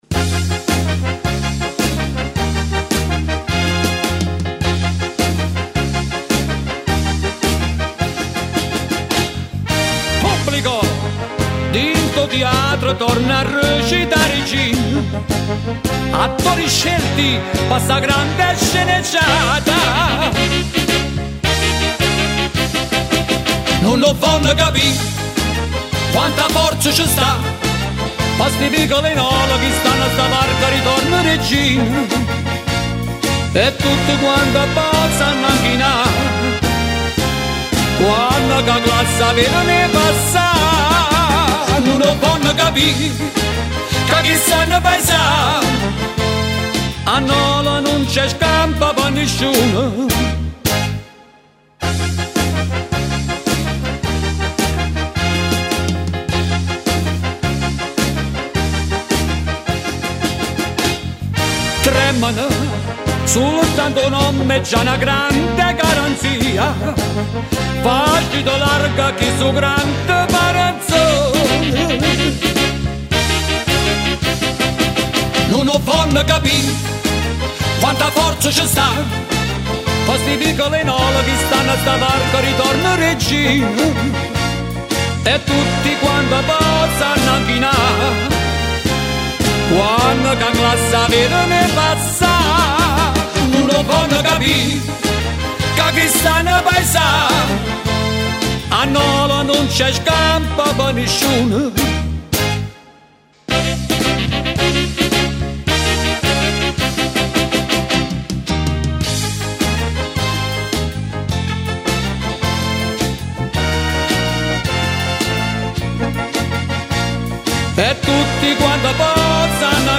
Divisione Musicale: De Angelis Band